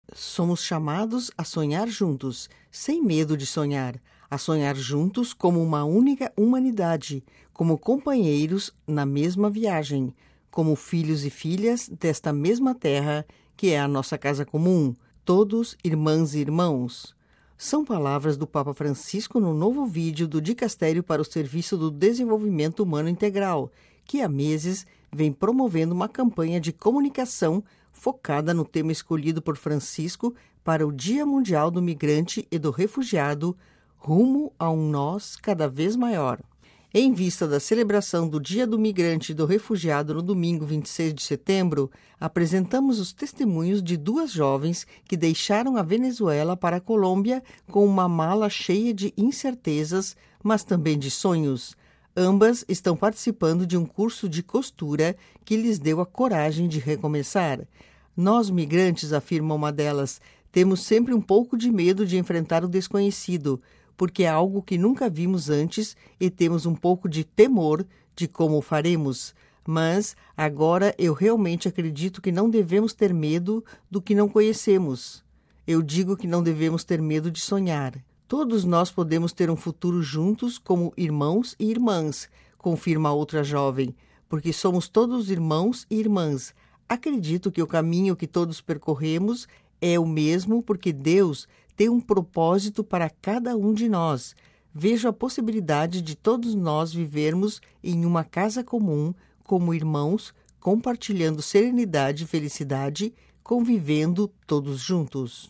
Em vista da celebração do Dia do Migrante e do Refugiado no domingo 26 de setembro, apresentamos os testemunhos de duas jovens que deixaram a Venezuela para a Colômbia com uma mala cheia de incertezas, mas também de sonhos. Ambas estão participando de um curso de costura que lhes deu a coragem de recomeçar.